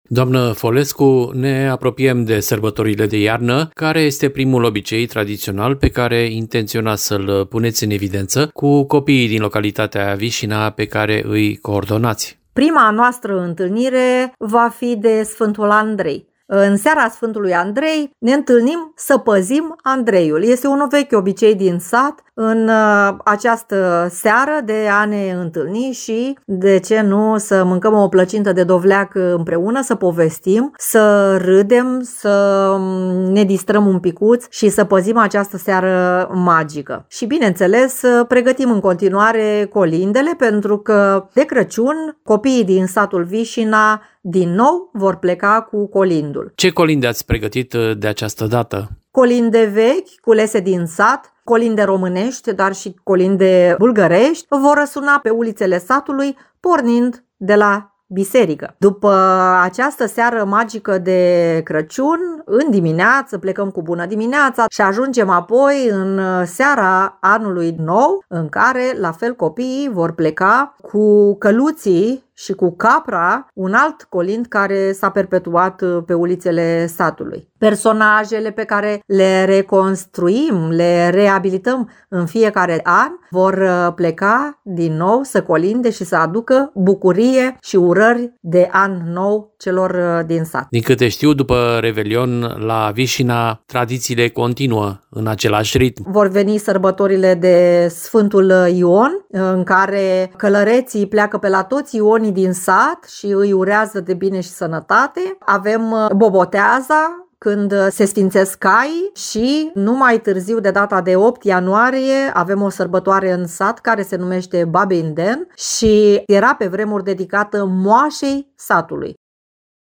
Mai multe detalii în interviul realizat în satul Vișina